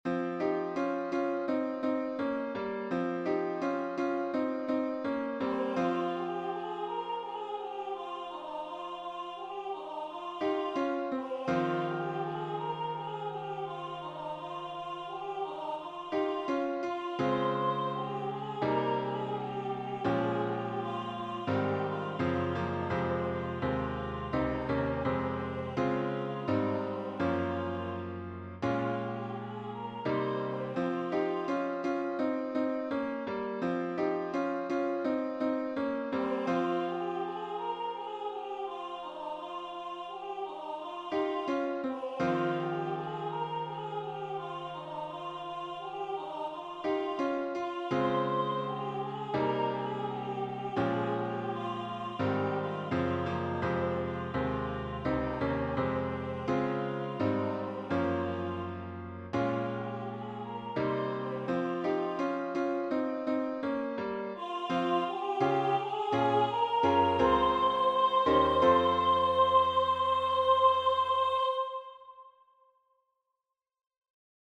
A new arrangement of the traditional Huron carol - Unison with piano accompaniment.